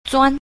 5. 鑽 – zuān – toàn